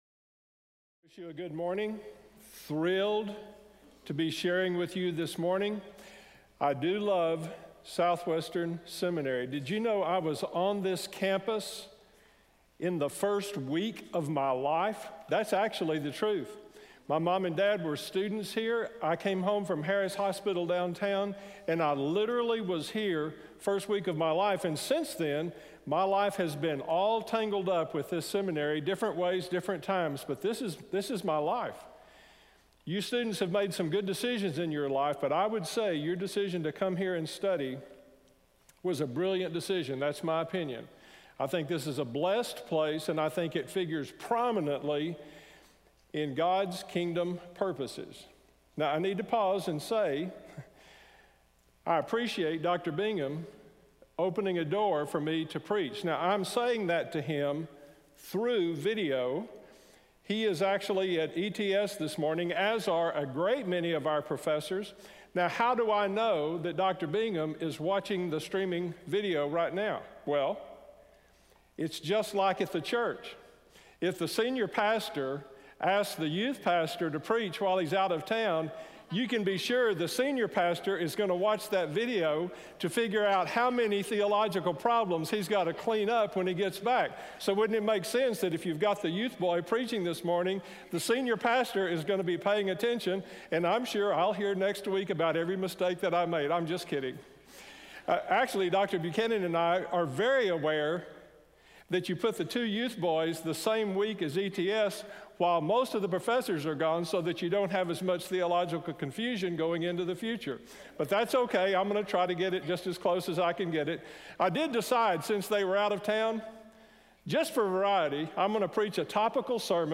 in SWBTS Chapel on Tuesday November 13, 2018
SWBTS Chapel Sermons